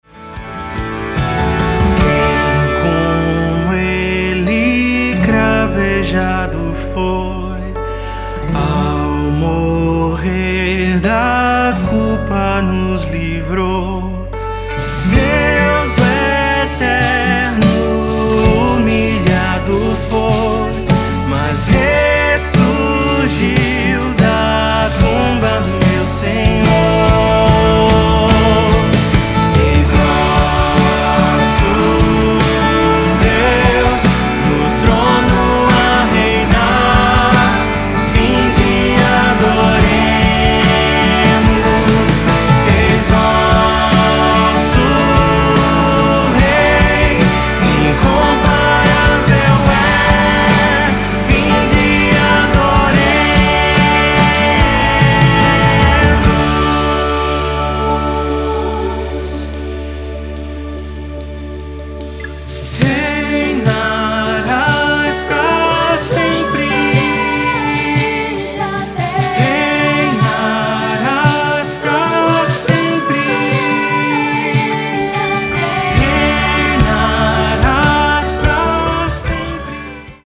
CIFRAS - TOM NO ÁLBUM (D)